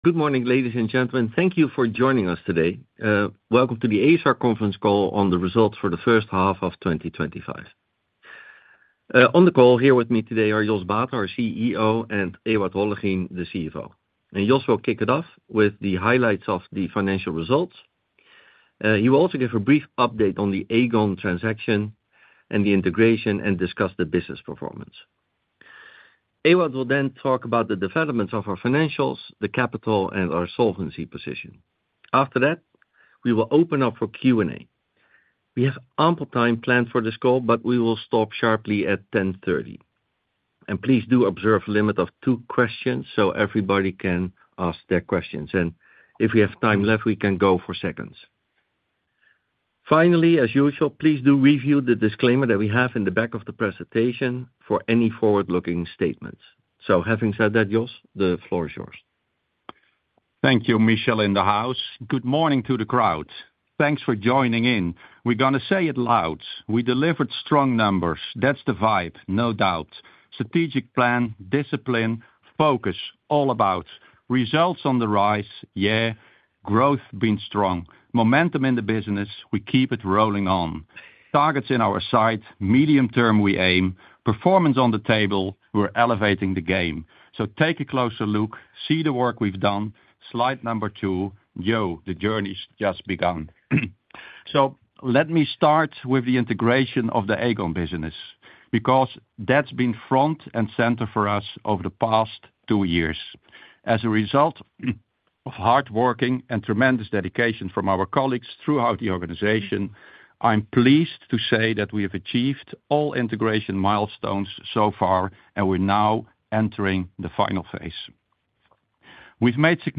2025-hy-conference-call-asr.mp3